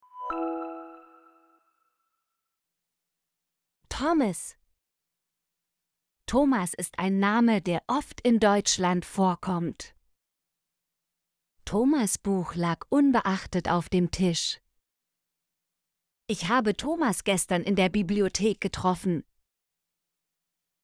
thomas - Example Sentences & Pronunciation, German Frequency List